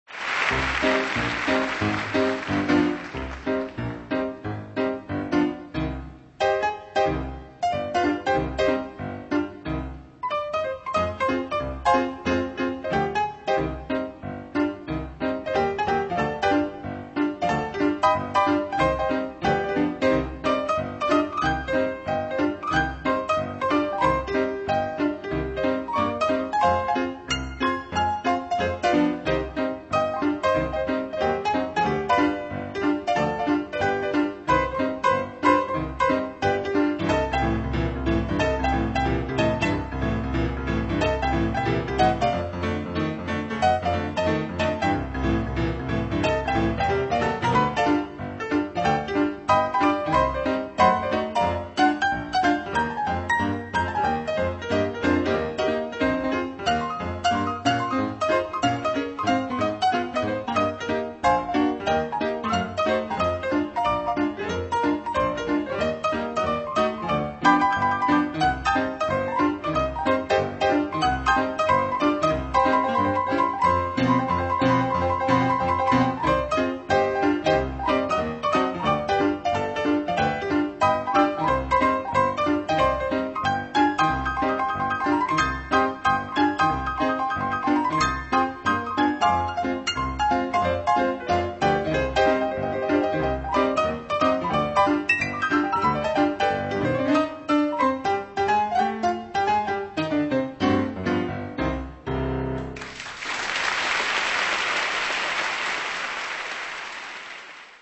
PIANO
Ragtime :